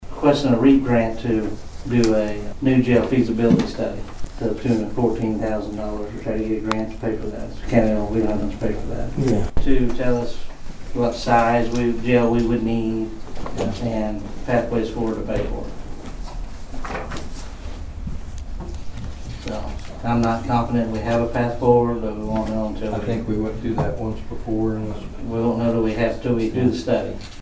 The Nowata County Commissioners met for a regularly scheduled meeting on Monday morning at the Nowata County Annex.
Sheriff Jason McClain talked about what the grant will be used for.